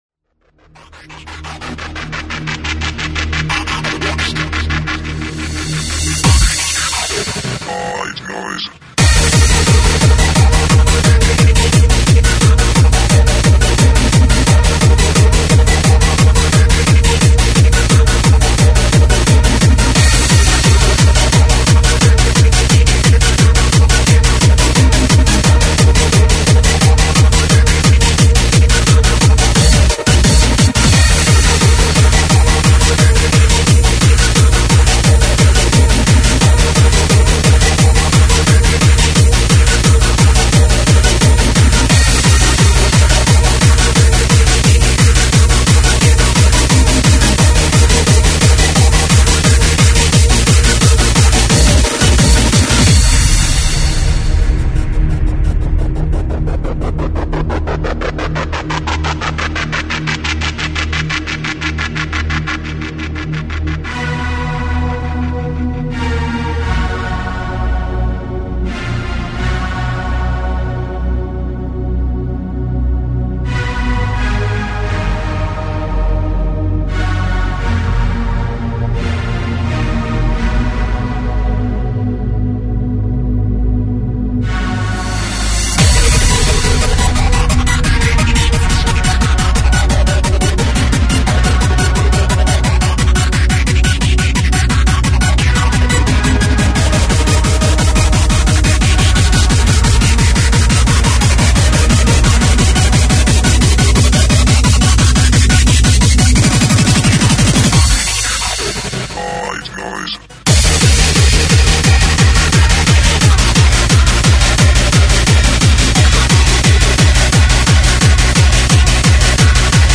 Freeform/Hardcore
Recorded at Kreatrix, Ottawa, Canada.